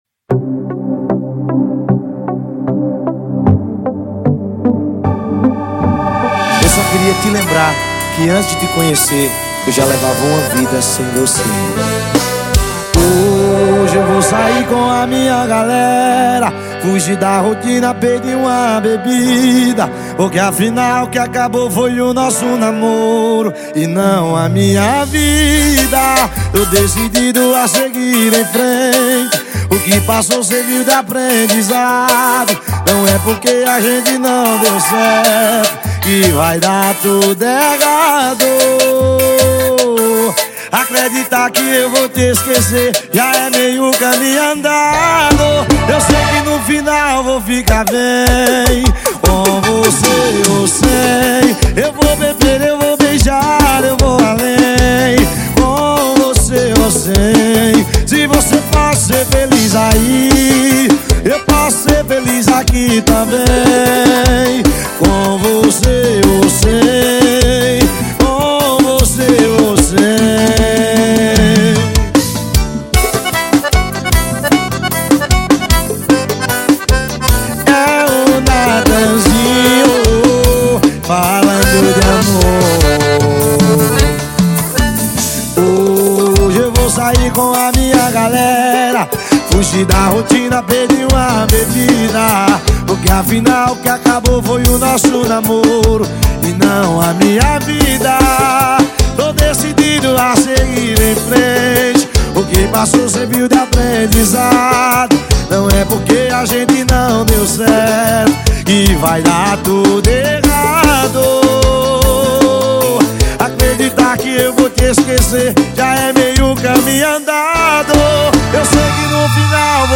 2024-11-07 11:00:54 Gênero: Forró Views